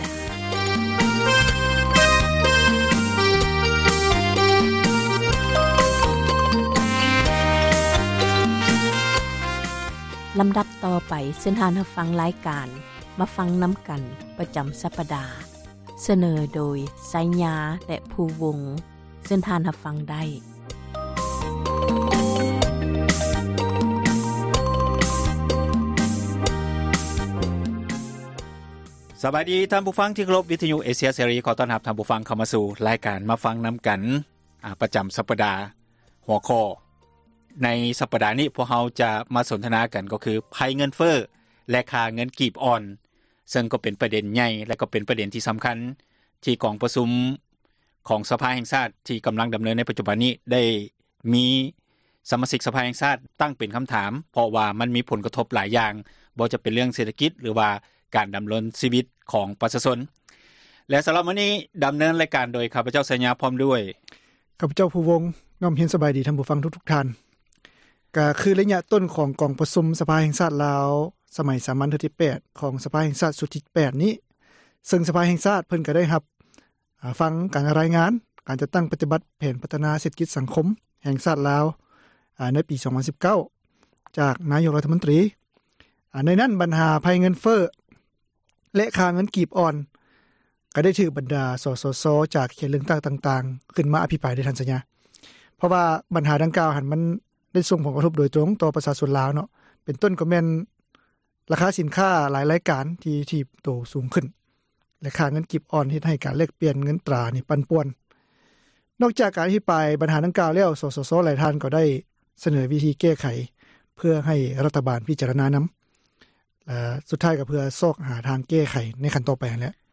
ແມ່ນຣາຍການສົນທະນາ ບັນຫາສັງຄົມ